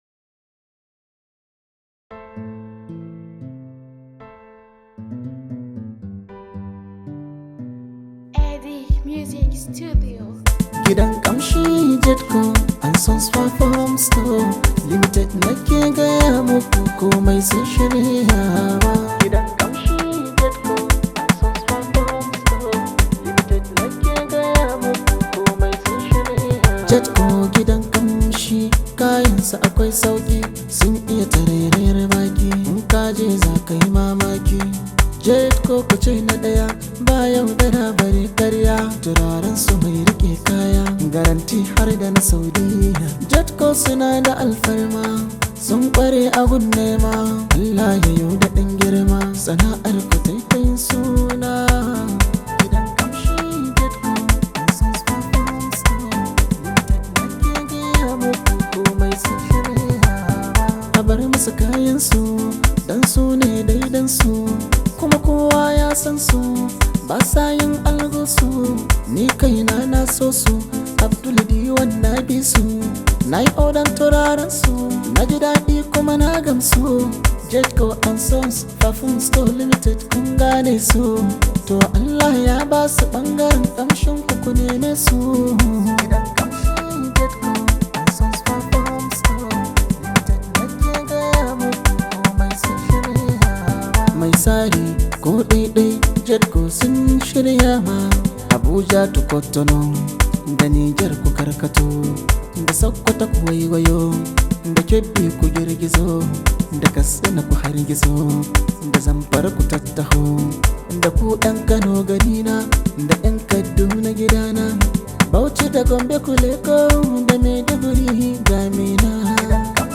hausa music track
an Arewa rooted song